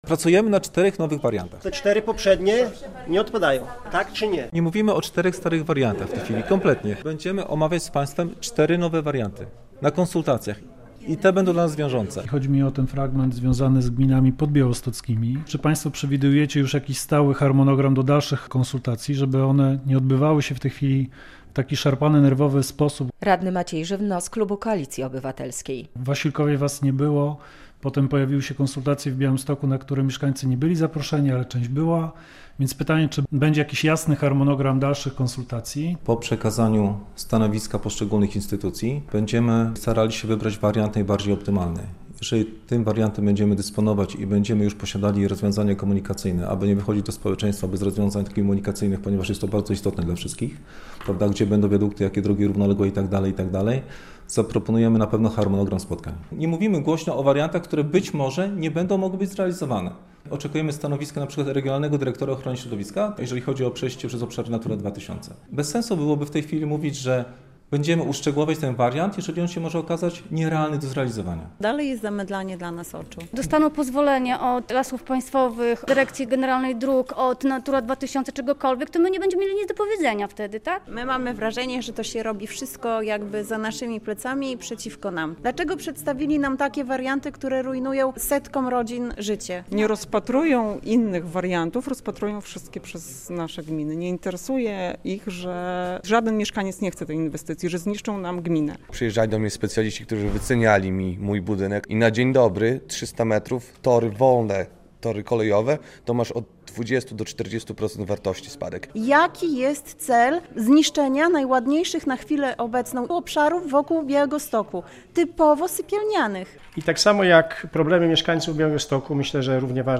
Długa dyskusja o przebiegu trasy Rail Baltica na sejmiku